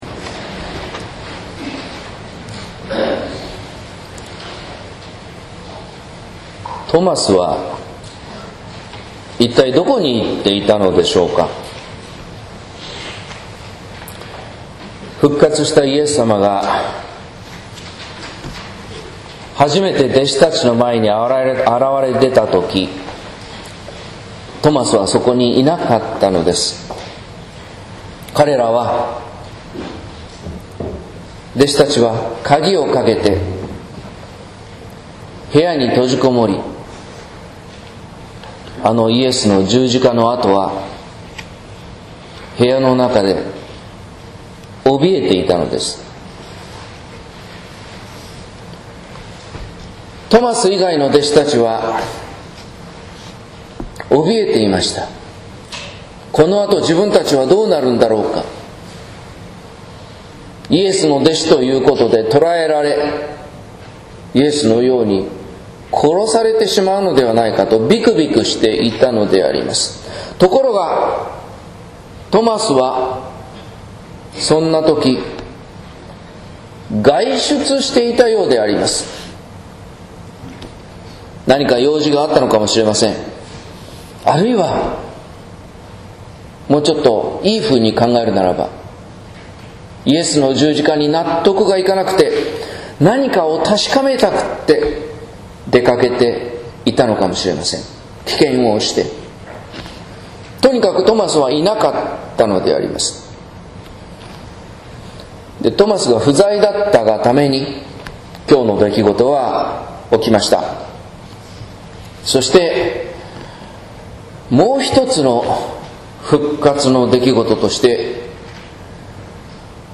説教「委ねて開く心の扉」（音声版）
復活後第２主日（2014年5月4日）